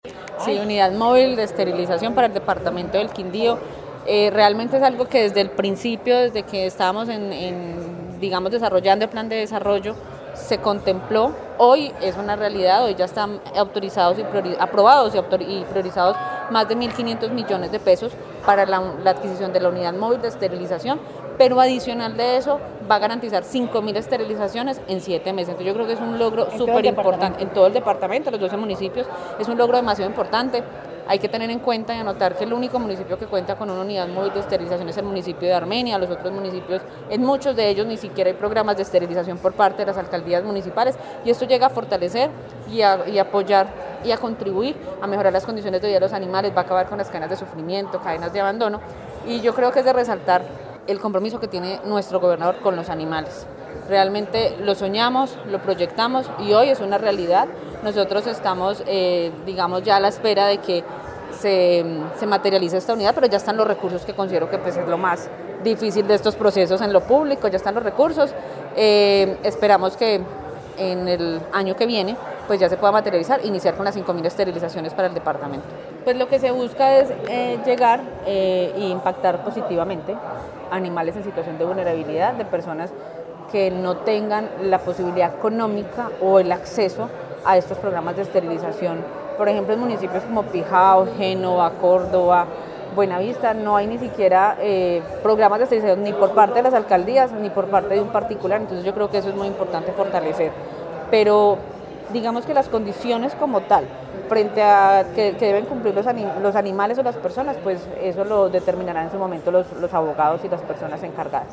Diputada Beatriz Aristizabal